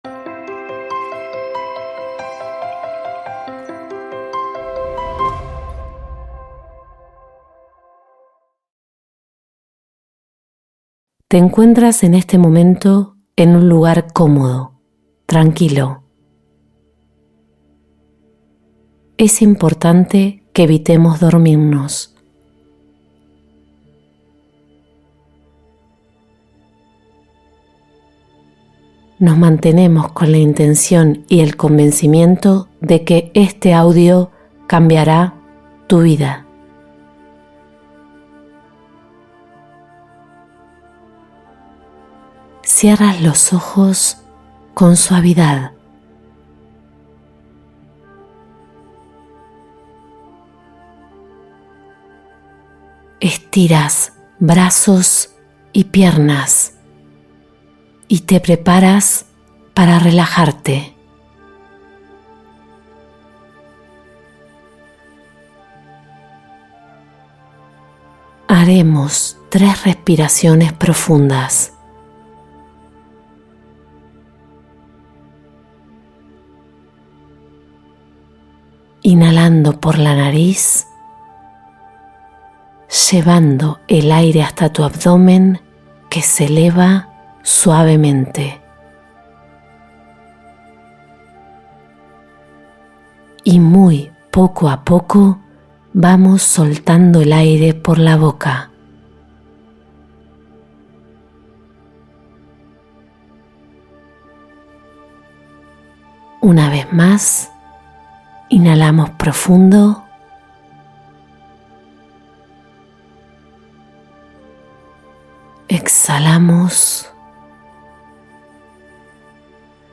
Meditación de Transformación Interna: Cambio desde la Conciencia